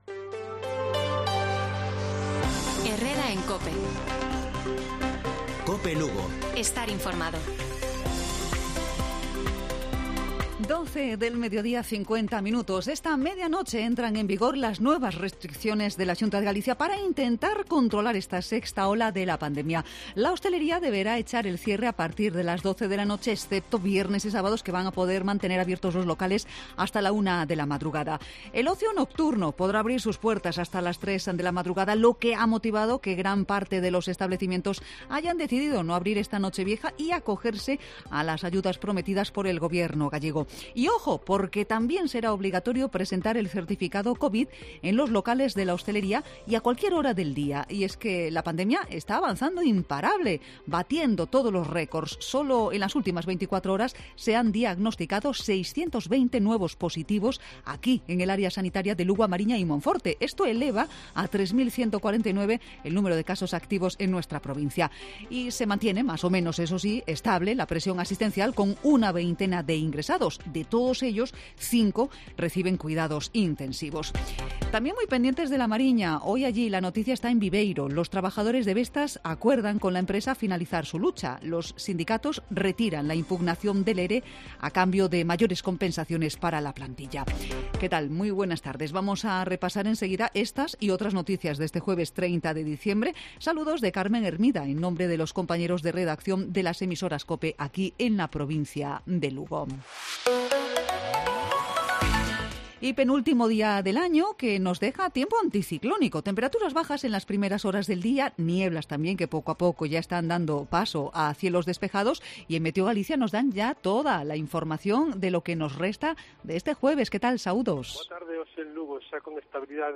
Informativo Provincial Cope Lugo. Jueves, 30 de diciembre. 12:50 horas